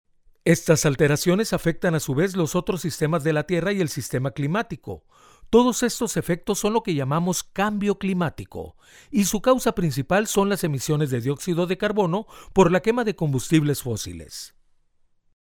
Mexican male voice talent